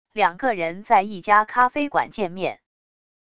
两个人在一家咖啡馆见面wav音频